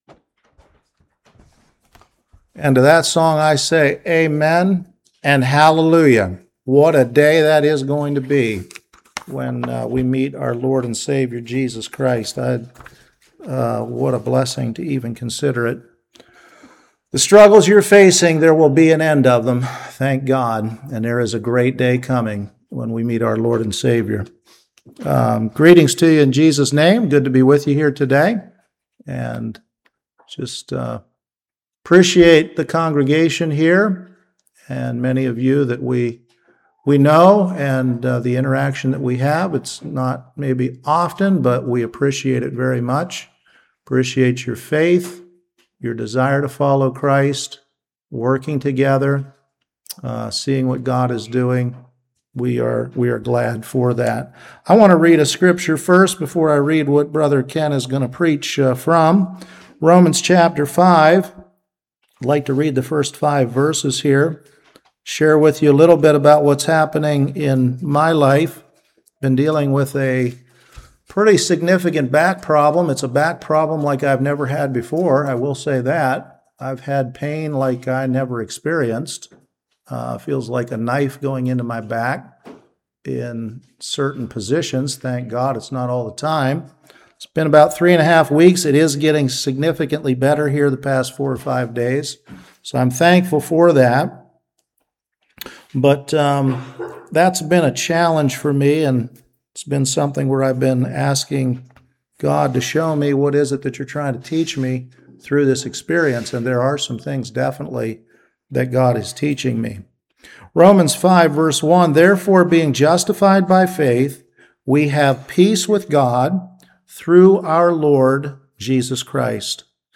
Ephesians 4:1-16 Service Type: Morning Christ’s Love for the church. 1.Love that paid the price. 2.